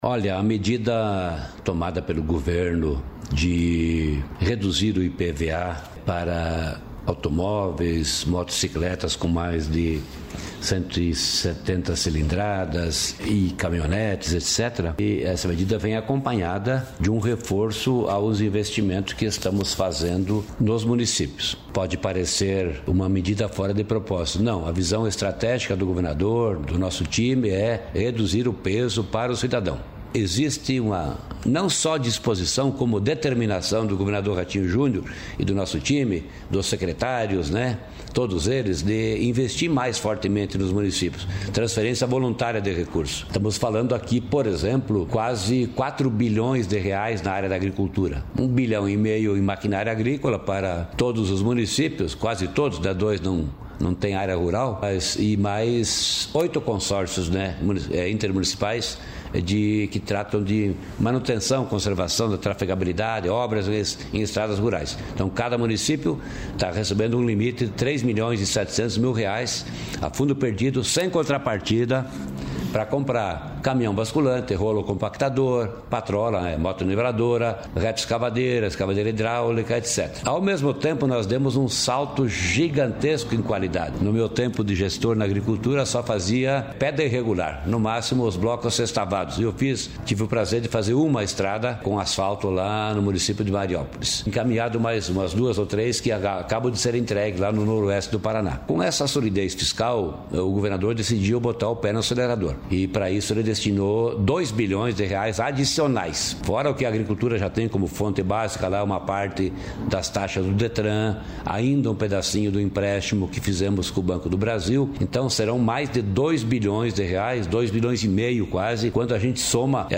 Sonora do secretário da Fazenda, Norberto Ortigara, sobre os investimentos do Estado nos municípios